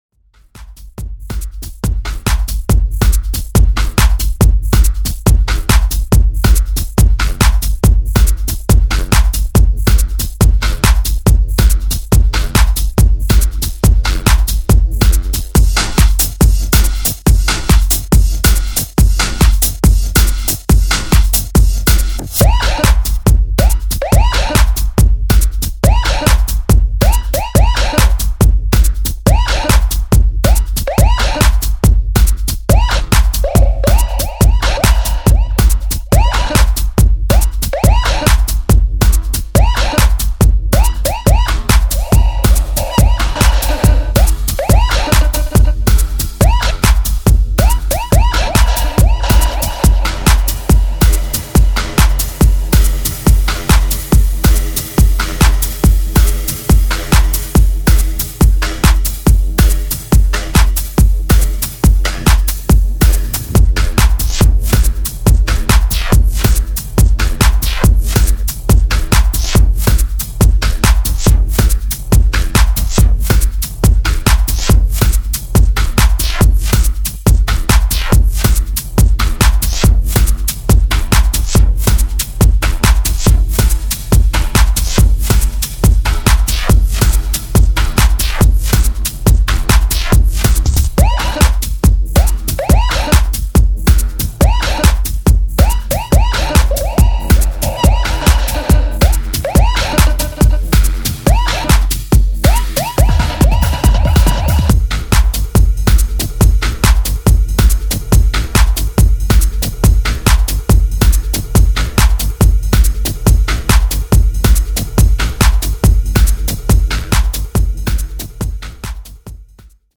前衛エレクトロニックからクラブ・レディなトラックまで幅広いサウンド・スペクトラムを収録。